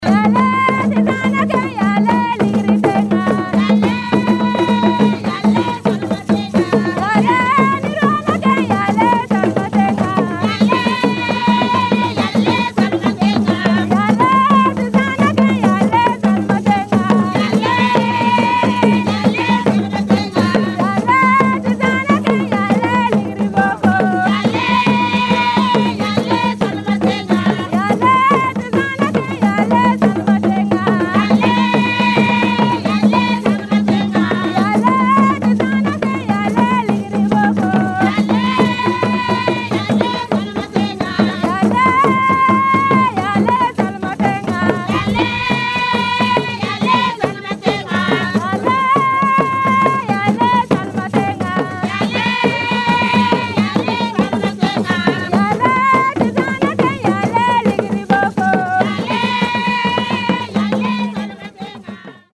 3. Vocal accompanied by drums
Here, too, the songs have a responsorial structure and in some cases the women 'ululate' with their tongue.
The siyarik provides additional rhythm.
frafra-womens-song-with-drums.mp3